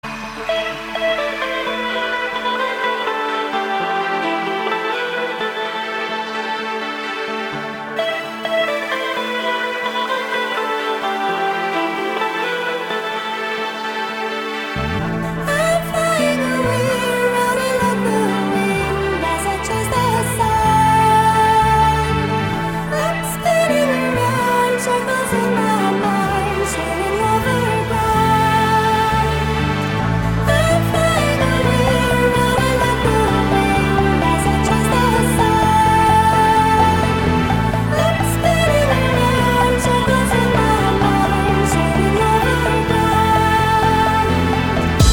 • Качество: 224, Stereo
красивые
dance
Electronic
спокойные
house